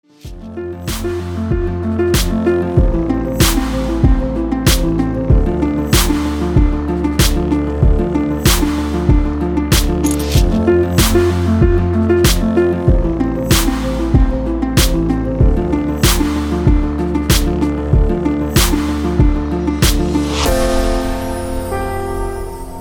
Android, Elektronisk musik